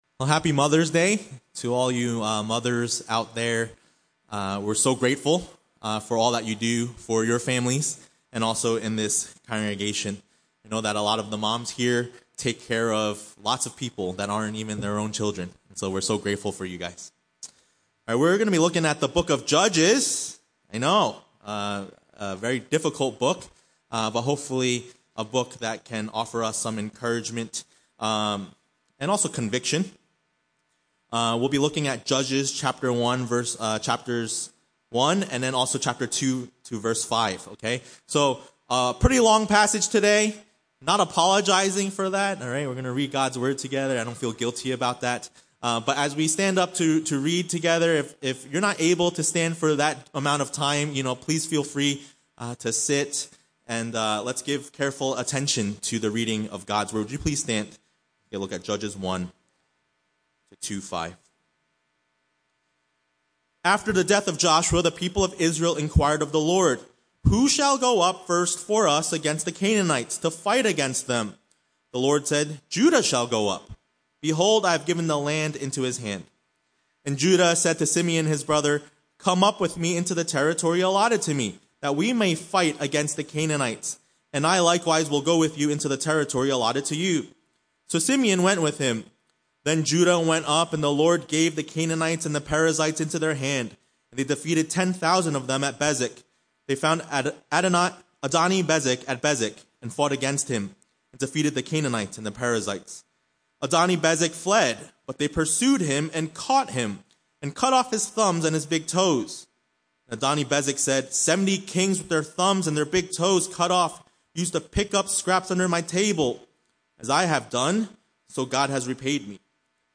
A message from the series "Judges."